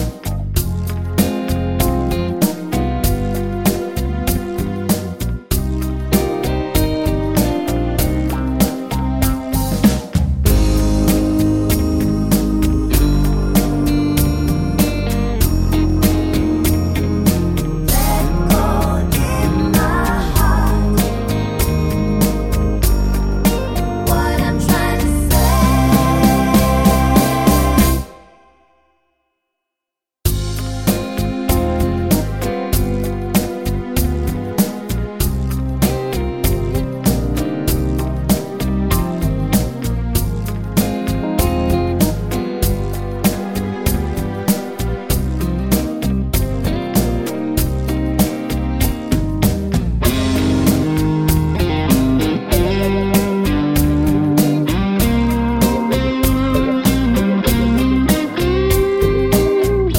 no Backing Vocals Soft Rock 4:23 Buy £1.50